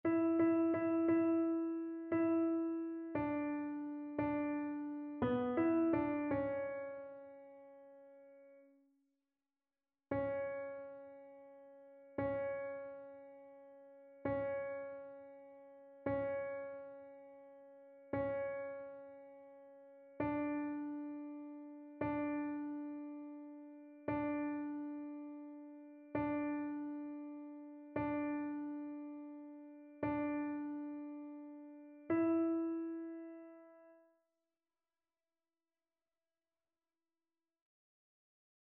annee-a-temps-ordinaire-26e-dimanche-psaume-24-alto.mp3